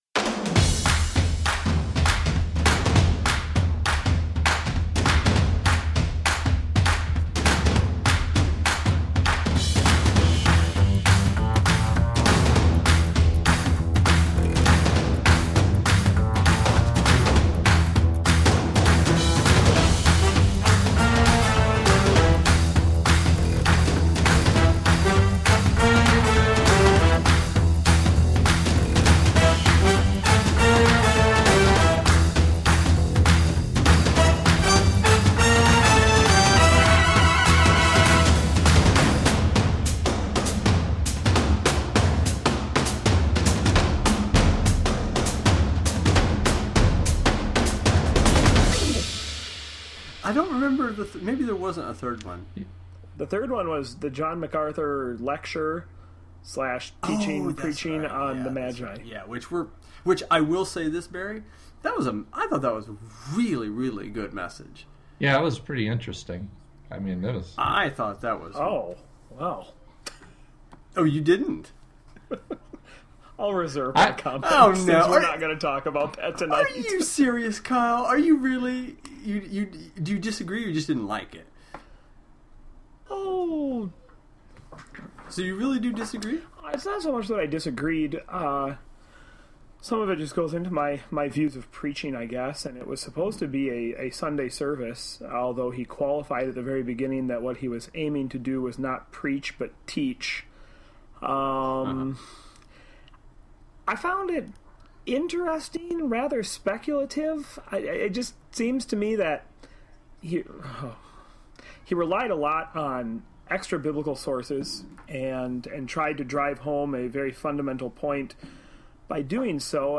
And it’s all set to a new, jazzy groove.